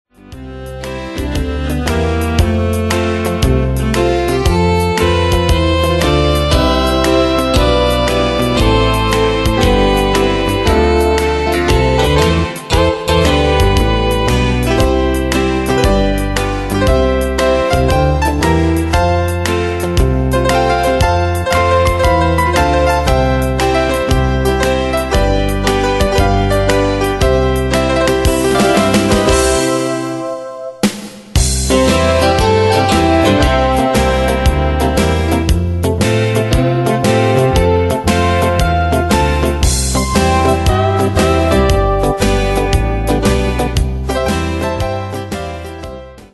Style: Country Année/Year: 1995 Tempo: 116 Durée/Time: 2.55
Danse/Dance: TwoSteps Cat Id.
Pro Backing Tracks